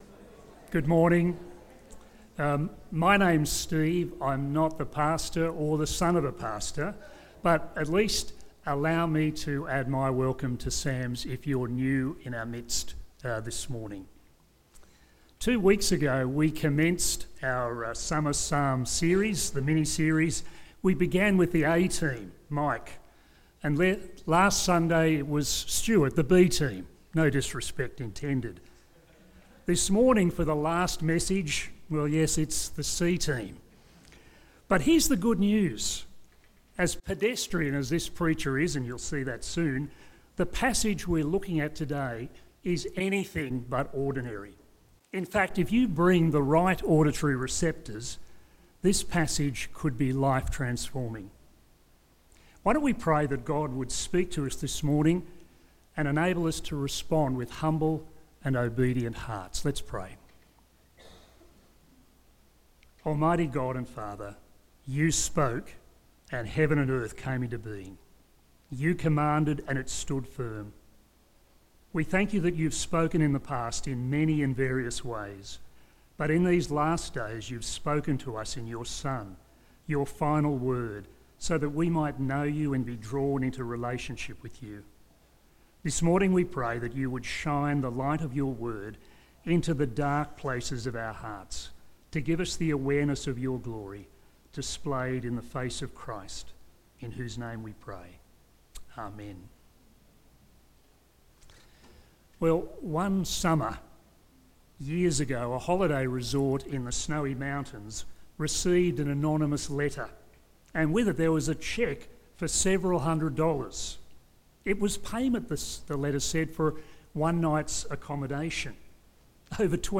Sermons
Listen to our sermons from Sunday here